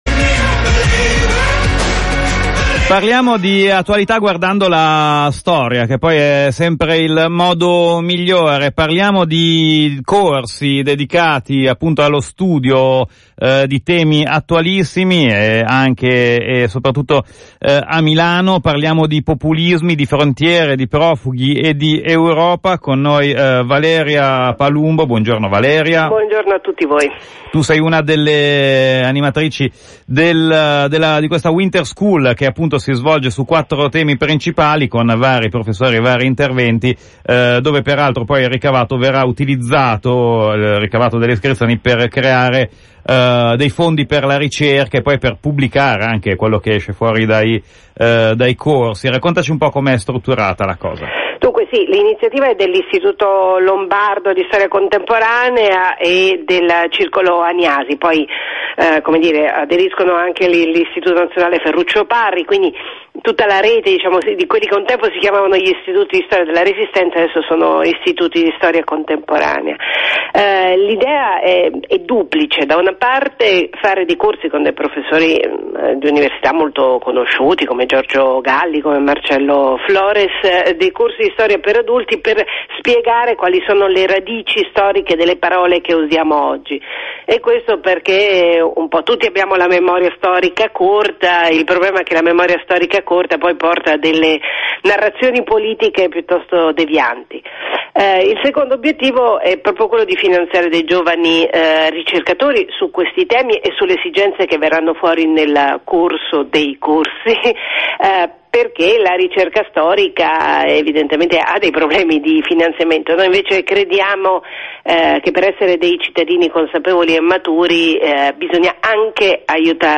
NELL’INTERVISTA SU RADIO POPOLARE: